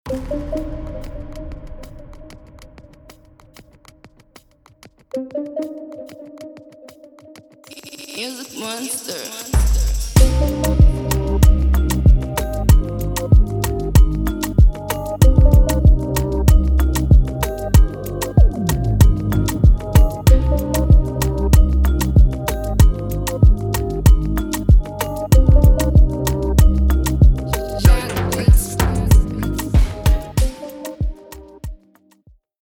Tempo: 95
Genre: Afrobeats